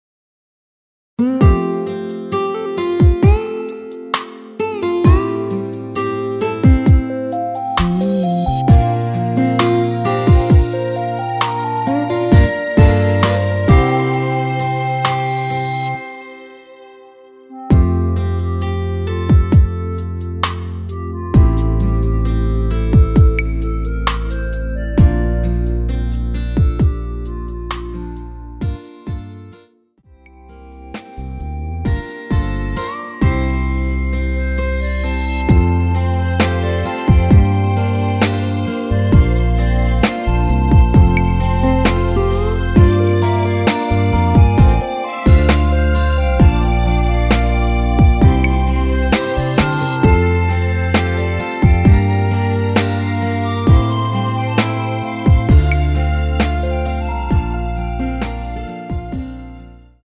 원키에서(+3)올린 멜로디 포함된 MR입니다.
앞부분30초, 뒷부분30초씩 편집해서 올려 드리고 있습니다.
중간에 음이 끈어지고 다시 나오는 이유는